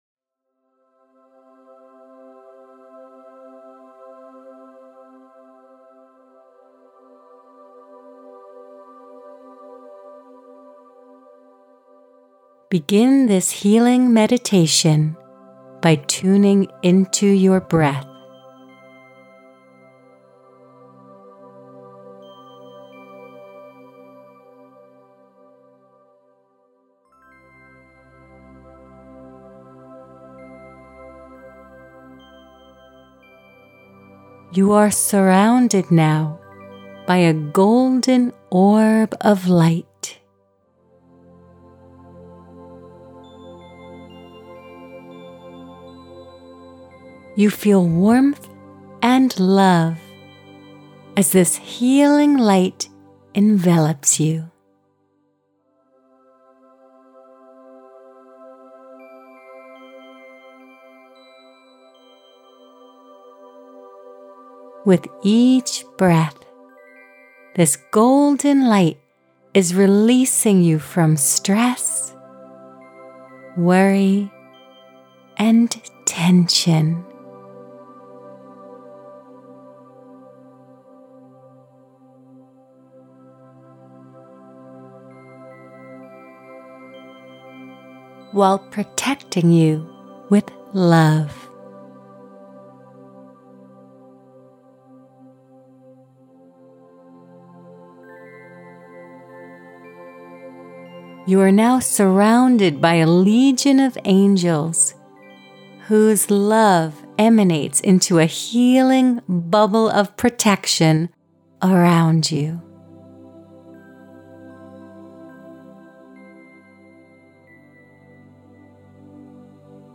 Meditation and hypnosis are powerful tools that support your wellness journey. Here you’ll find a treasure trove of soothing audio journeys designed to cultivate a sense of calm and confidence.